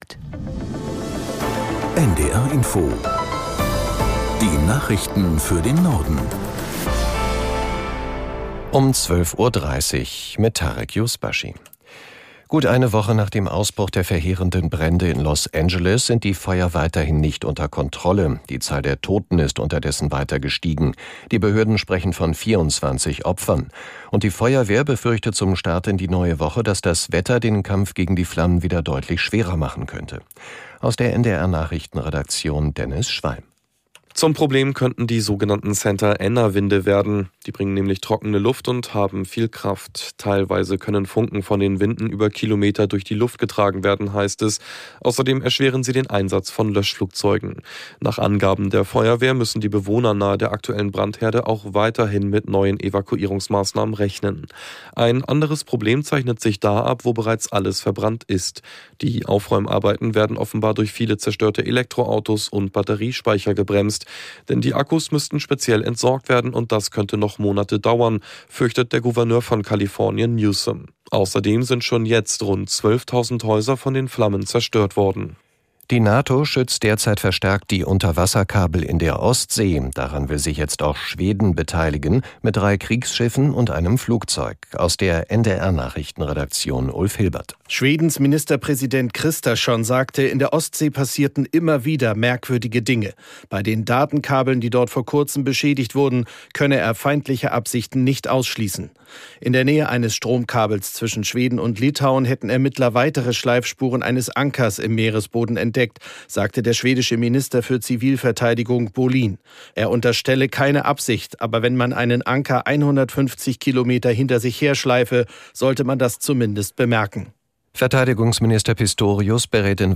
Nachrichten NDR Info Tägliche Nachrichten